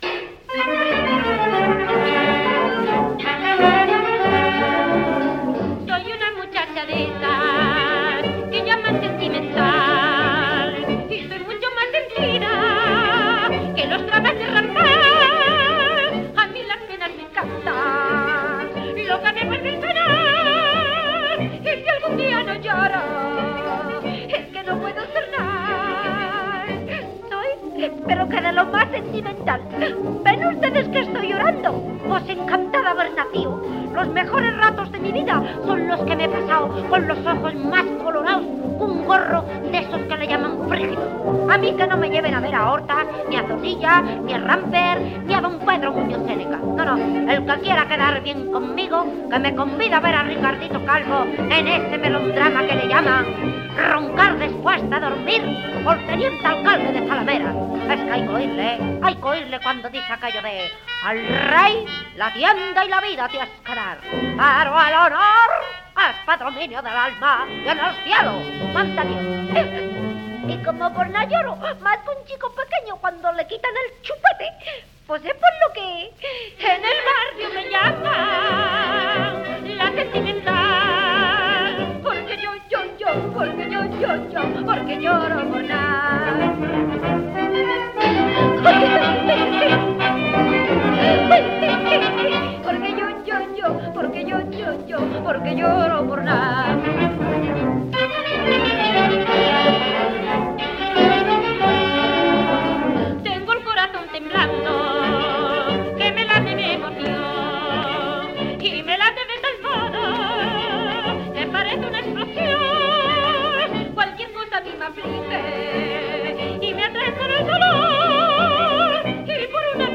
fox-trot
orquesta [78 rpm